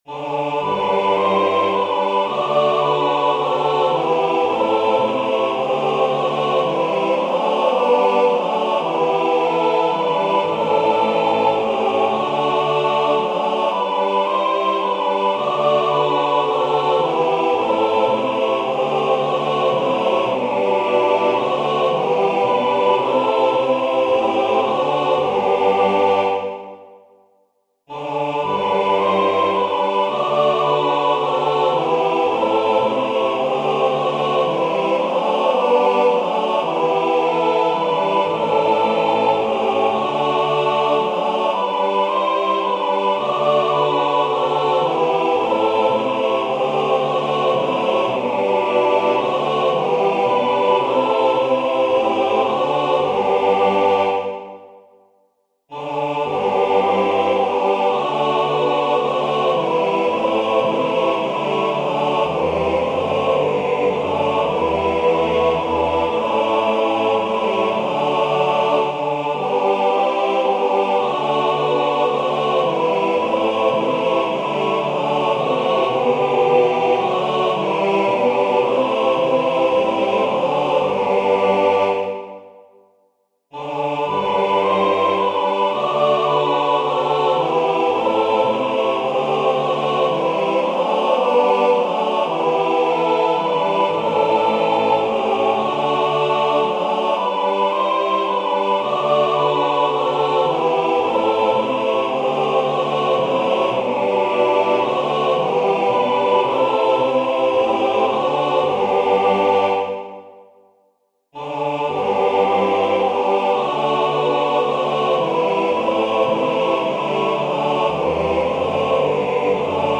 Vers 2: p. Vanaf maat 9 langzaam crescendo tot mf op de slotnoot
Meezingen